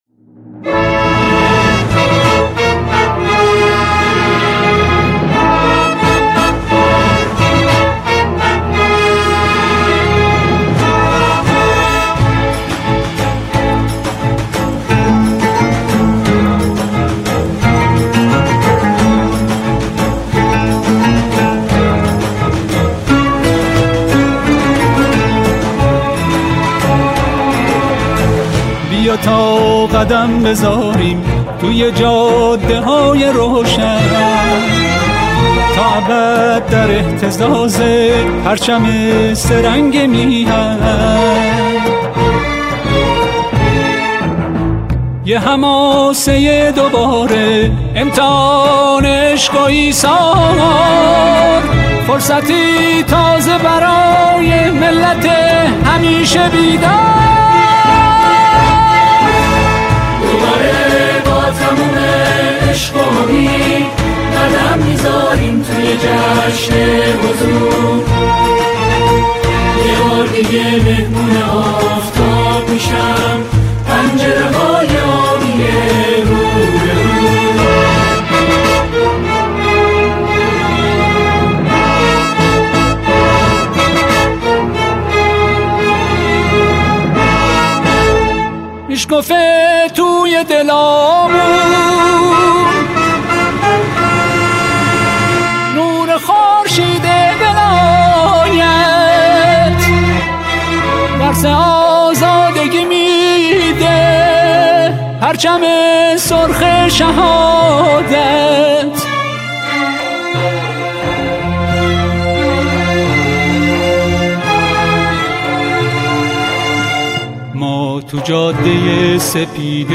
خواننده، این قطعه را با شعری درباره انتخابات اجرا می‌کند.